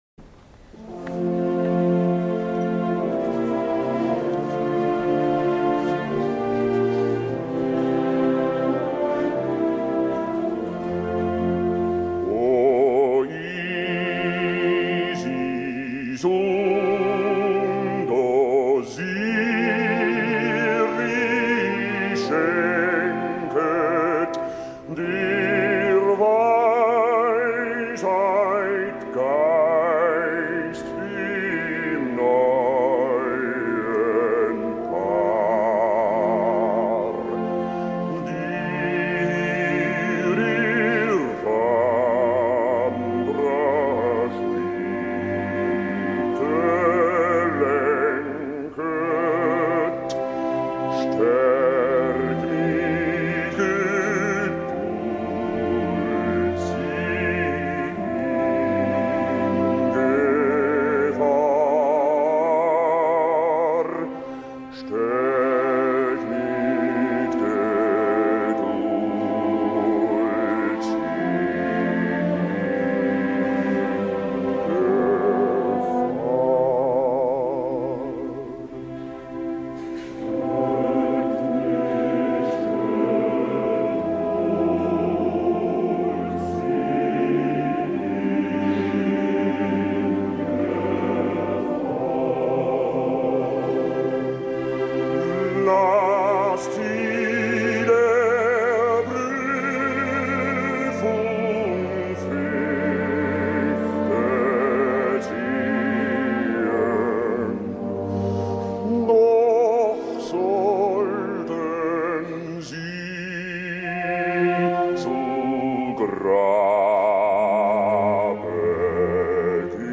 Voicing: Solo & Kbd